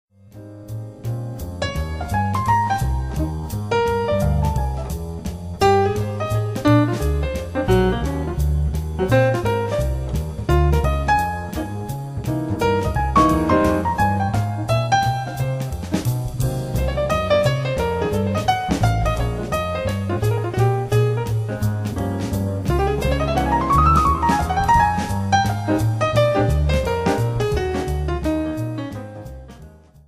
四、採用爵士樂中最受人喜愛的 鋼琴、鼓、貝斯 三重奏編制，演奏受人喜愛的旋律